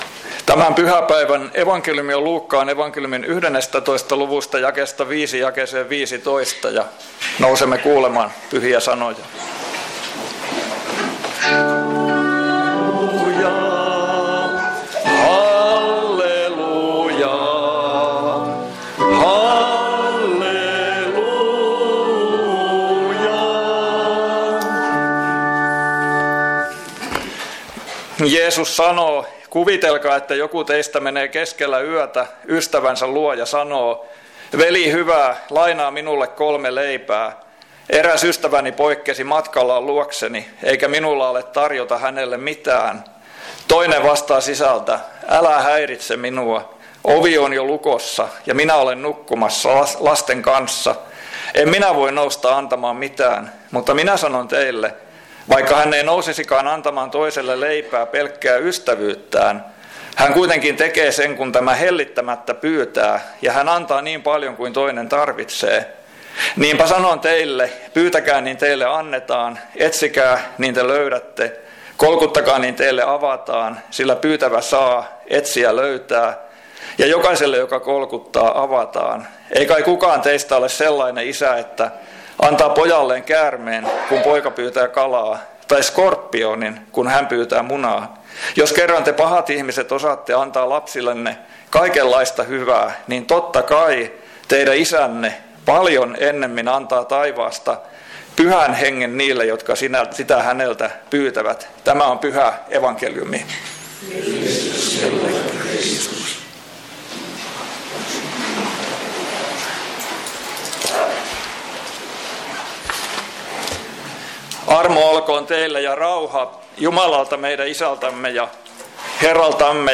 Kokoelmat: Seinäjoki Hyvän Paimenen kappelin saarnat